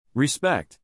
respect ” has a stress on the second syllable both when it’s a verb and a noun.